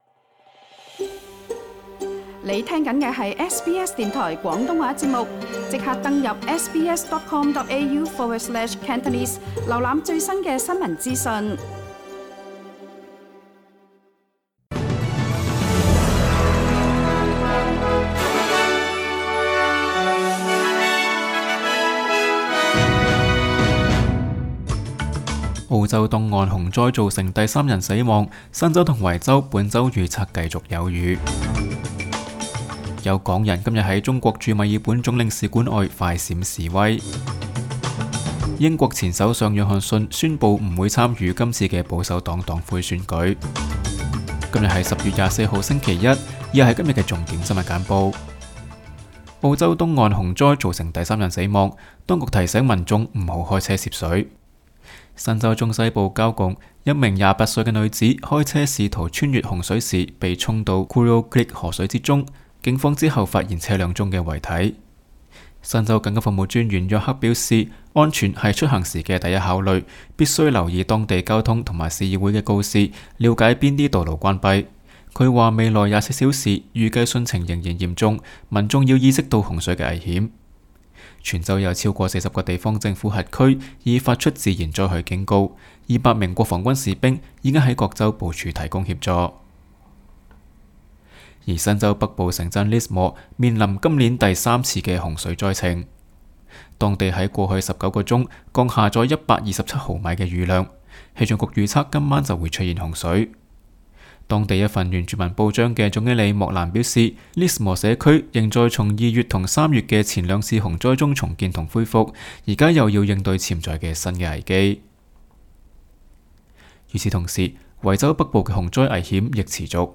SBS 新聞簡報（10月24日）
SBS 廣東話節目新聞簡報 Source: SBS / SBS Cantonese